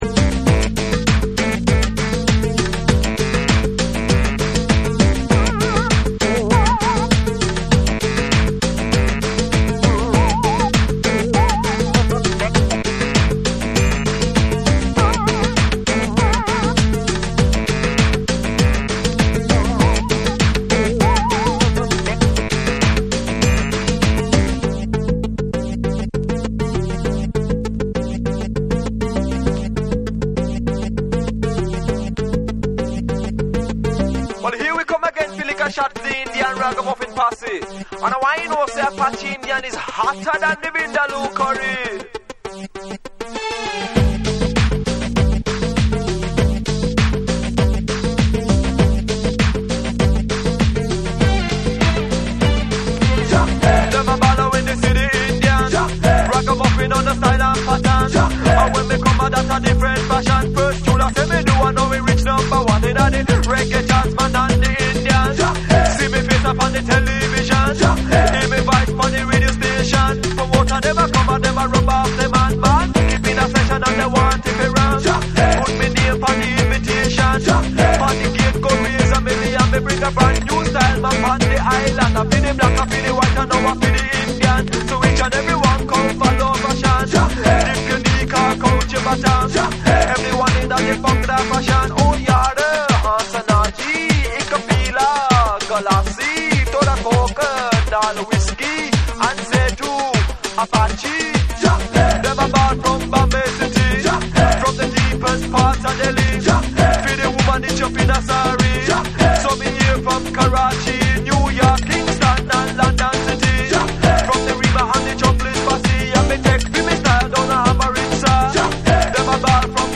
90年代初頭より活動するインド系ラガDEE JAY
REGGAE & DUB / ORGANIC GROOVE